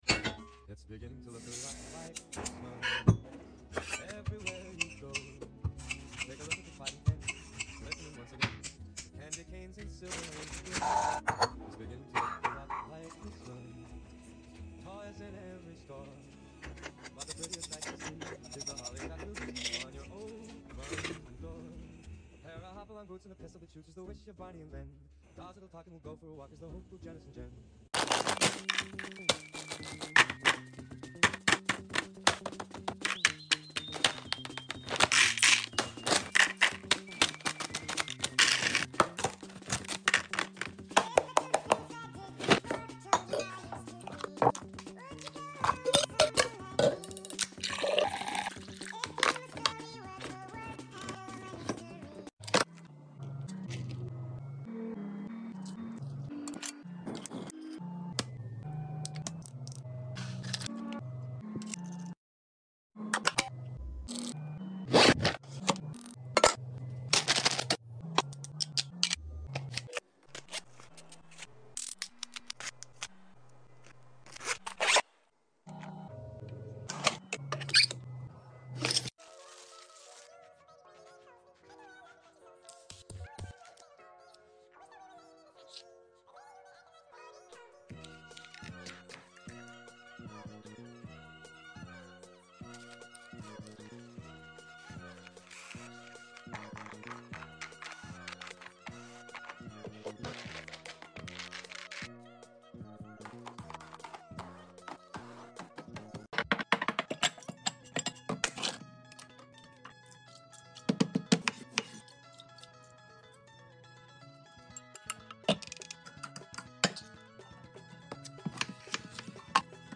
Lunch box and cooking asmr sound effects free download